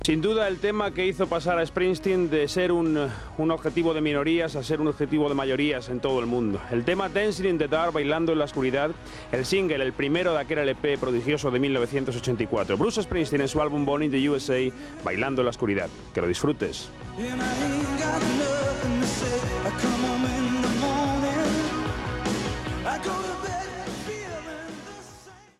Presentació d'un tema musical.
Musical